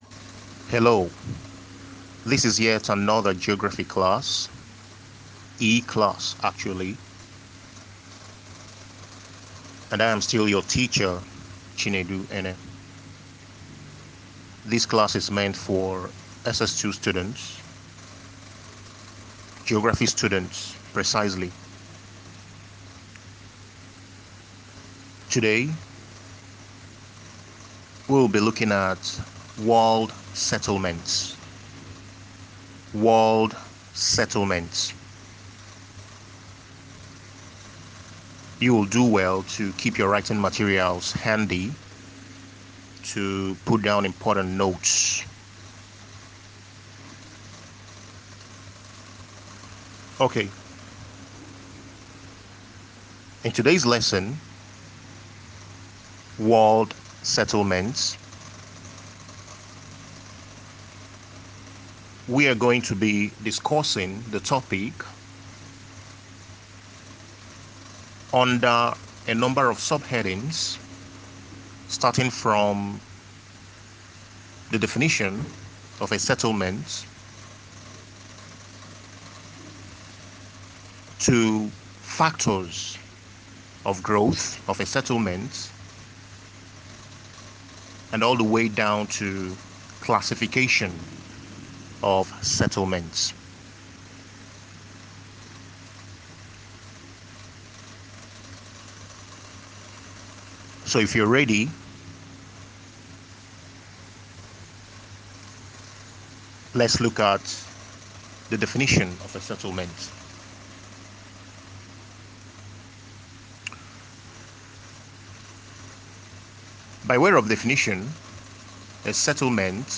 Attached herein is an audio tutorial on Geography for ss 2.